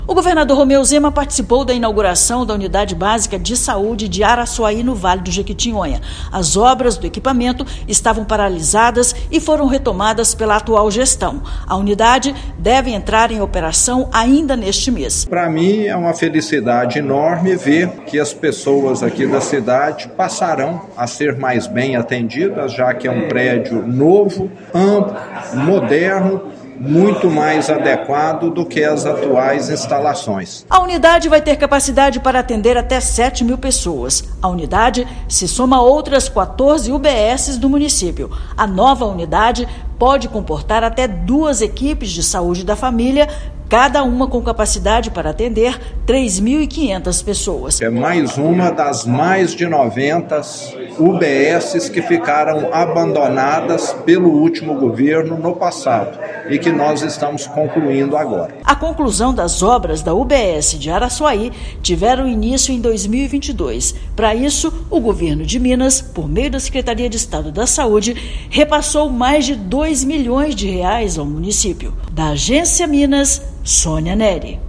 Unidade Básica de Saúde já está com as obras em finalização e vai beneficiar cerca de 7 mil habitantes do município. Ouça matéria de rádio.